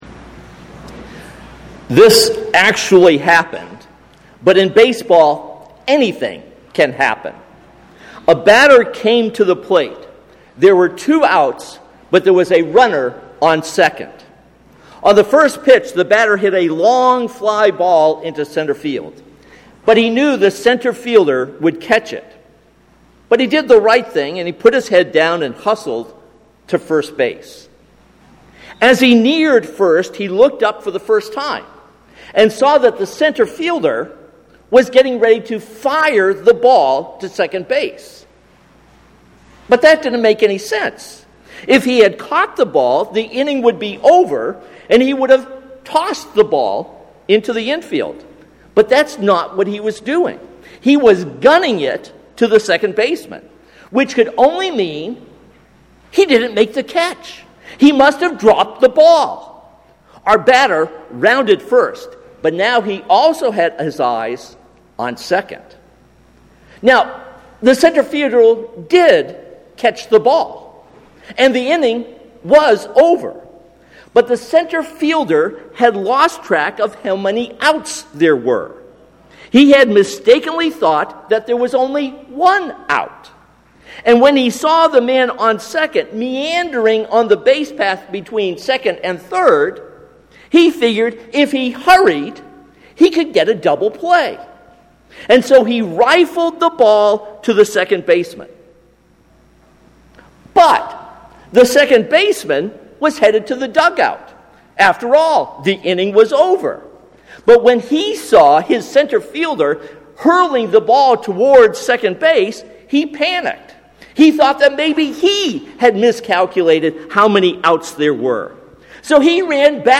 This sermon concludes our series and is based on Mark 9:17-29.